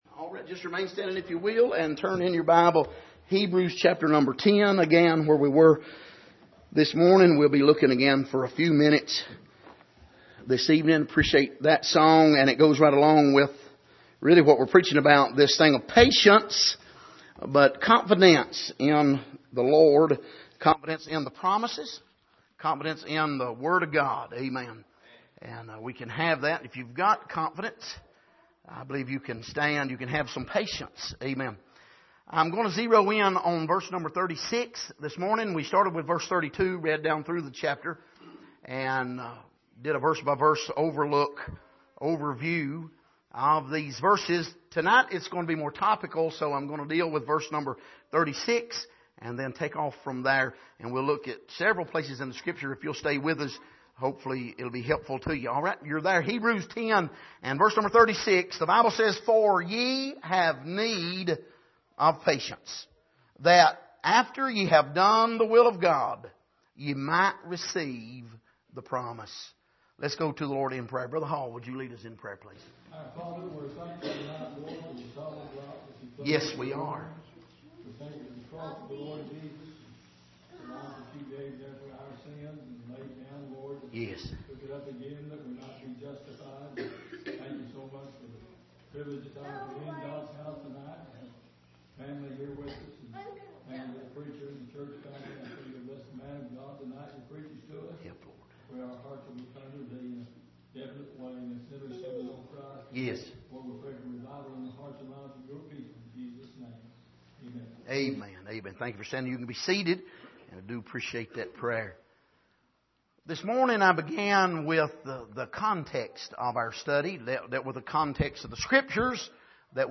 Passage: Hebrews 10:32-39 Service: Sunday Evening